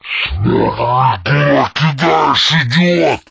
izlome_hit_3.ogg